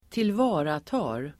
Uttal: [²tilv'a:rata:r]